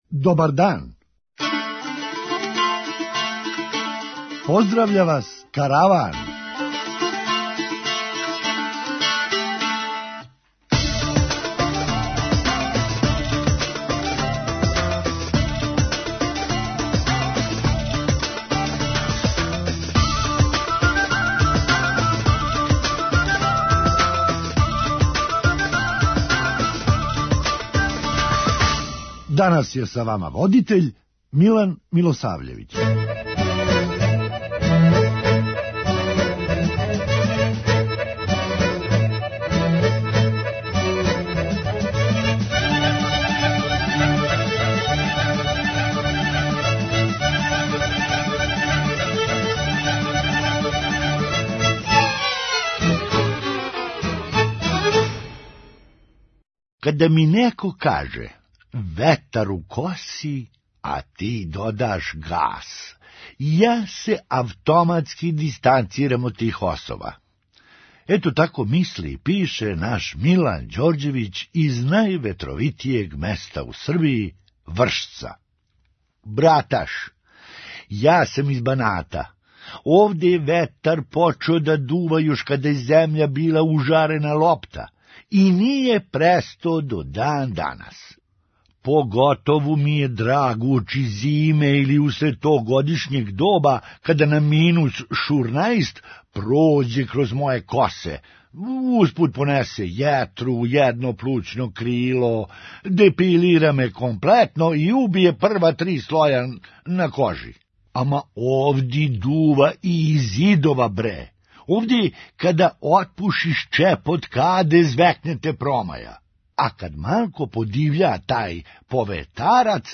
Хумористичка емисија
Па још ако тамо затекну изабраног лекара – тим боље! преузми : 8.96 MB Караван Autor: Забавна редакција Радио Бeограда 1 Караван се креће ка својој дестинацији већ више од 50 година, увек добро натоварен актуелним хумором и изворним народним песмама.